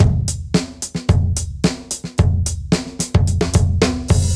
Index of /musicradar/sampled-funk-soul-samples/110bpm/Beats